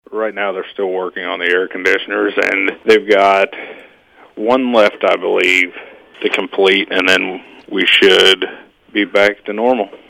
Saline County Sheriff Daniel Kirchhoff spoke to KMMO News about the on-going work at the Saline County Jail.